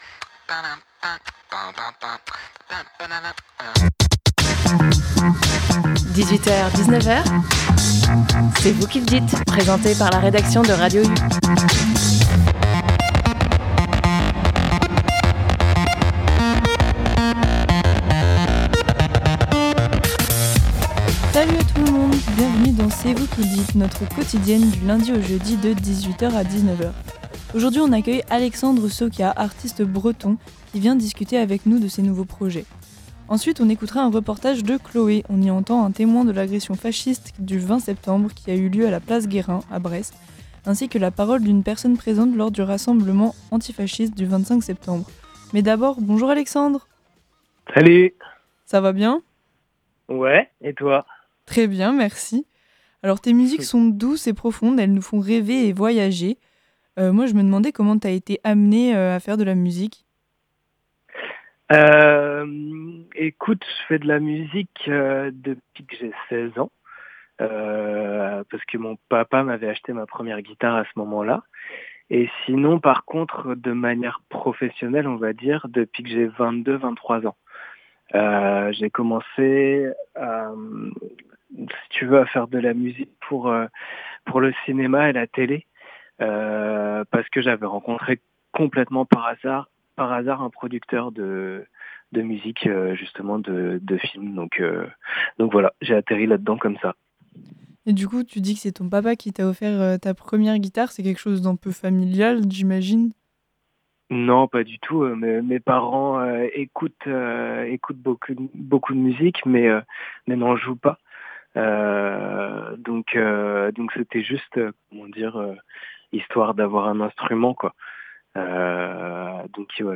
Aujourd’hui, on a reçu au téléphone
Elle a pu parler avec une Brestoise sur la notion de manifester.